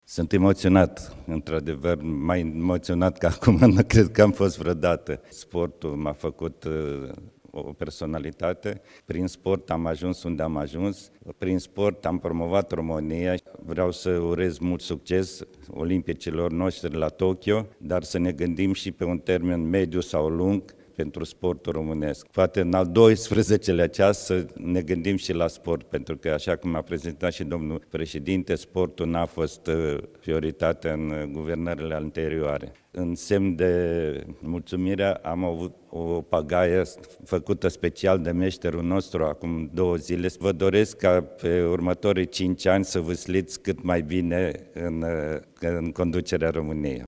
26nov-13-Patzaichin-emotionat.mp3